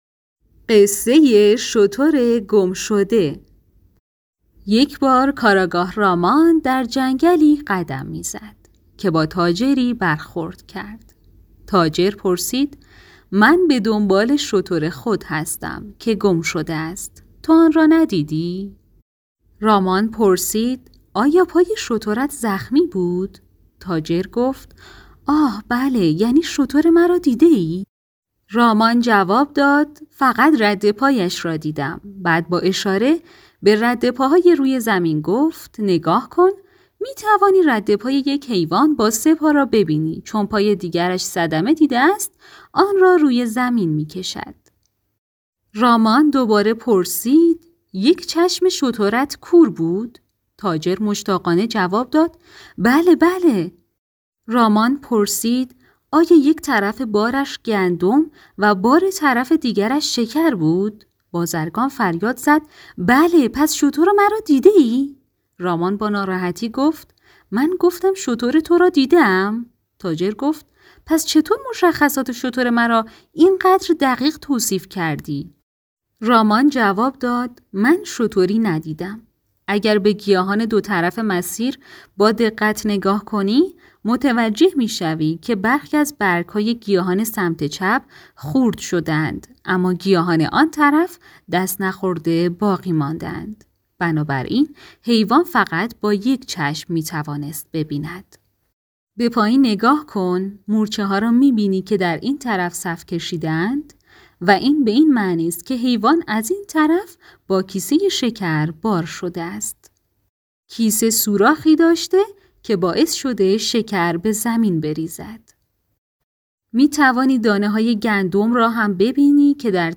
قصه های کودکانه